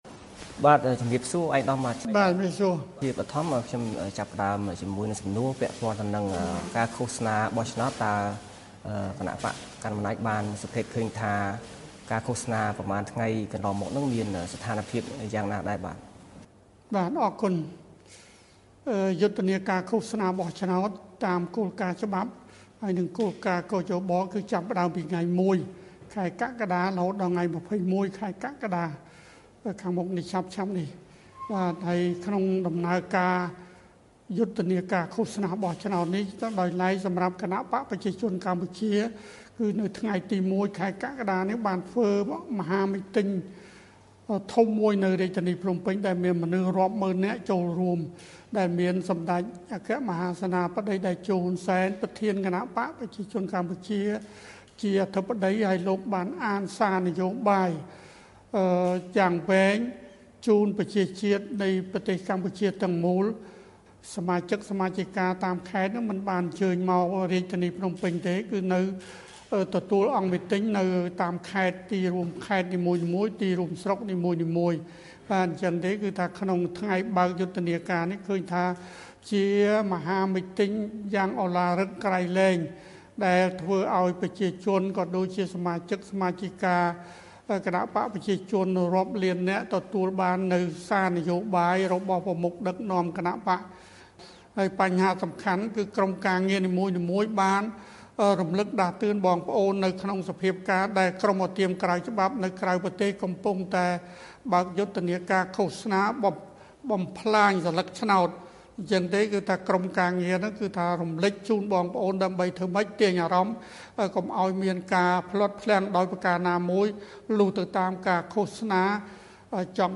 បទសម្ភាសន៍ VOA៖ គណបក្សកាន់អំណាចការពារដំណើរការរៀបចំការបោះឆ្នោត ដែលគ្មានគណបក្សជំទាស់ចូលរួម